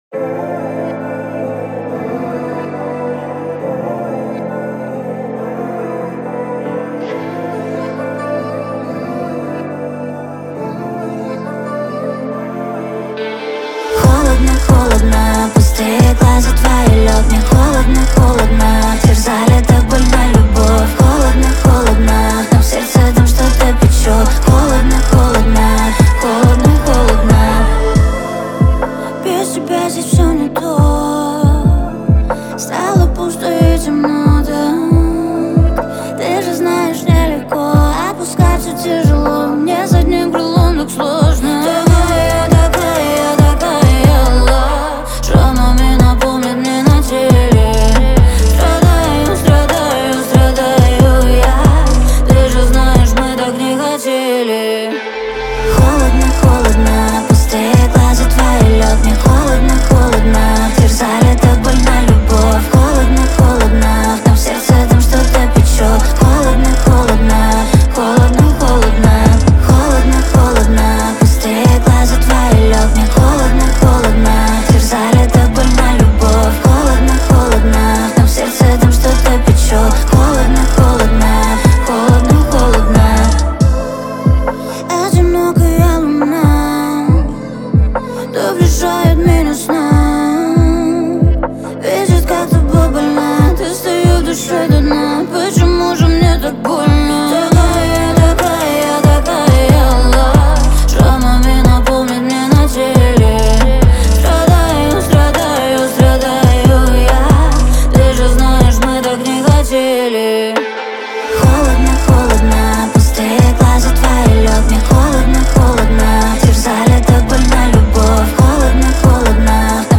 это атмосферный трек в жанре инди-поп